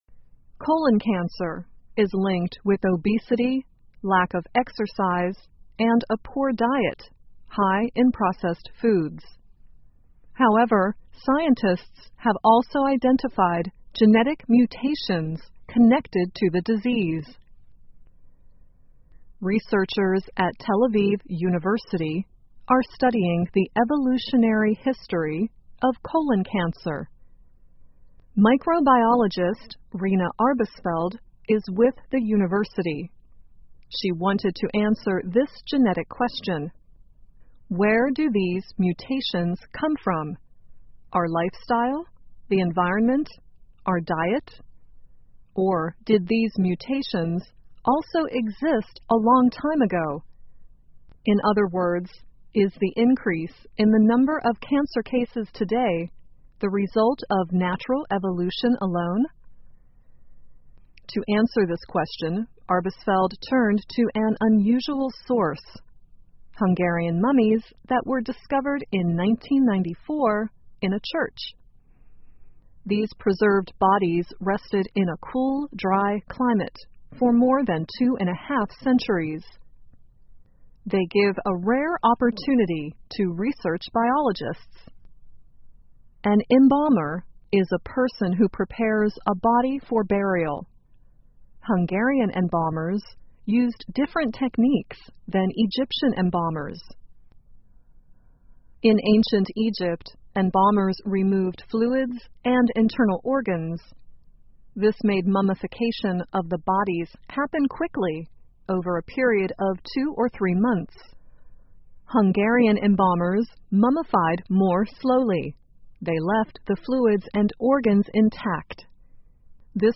在线英语听力室木乃伊包含了结肠癌的线索的听力文件下载,2016年慢速英语(三)月-在线英语听力室